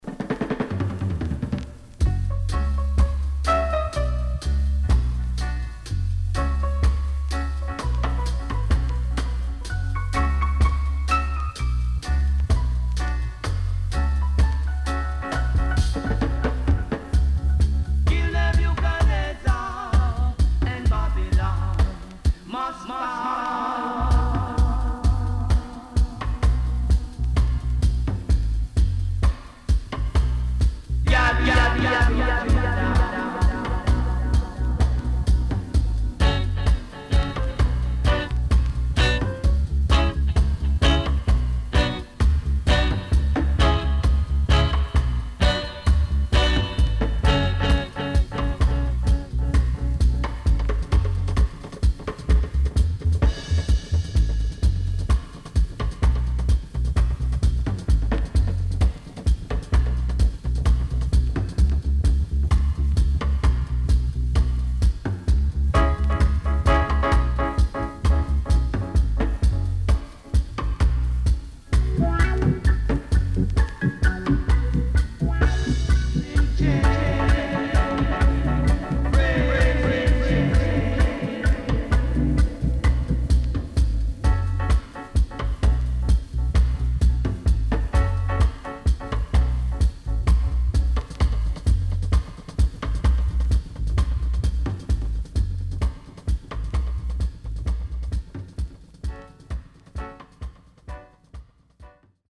SIDE A:ビニール焼けによりノイズ入ります。